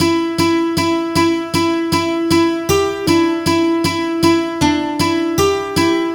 Track 11 - Guitar 02.wav